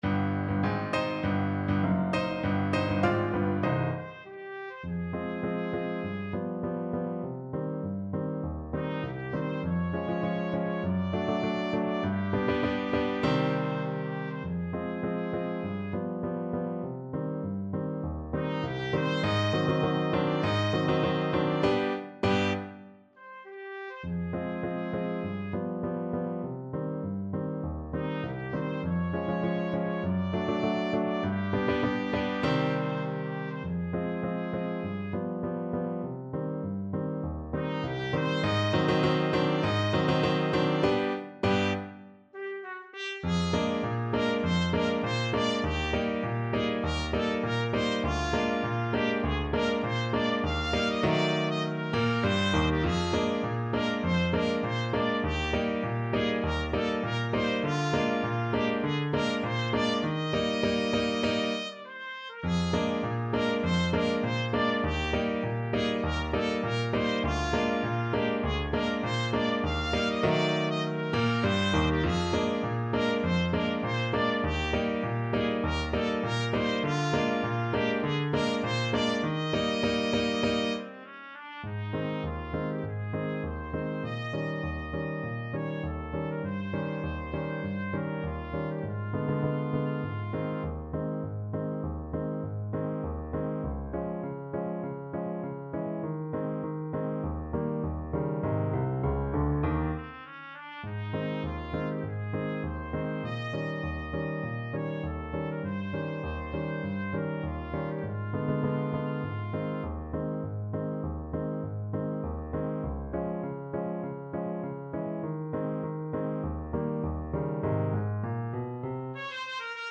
Play (or use space bar on your keyboard) Pause Music Playalong - Player 1 Accompaniment reset tempo print settings full screen
Quick March = c. 100
C minor (Sounding Pitch) (View more C minor Music for Trumpet-French Horn Duet )
Classical (View more Classical Trumpet-French Horn Duet Music)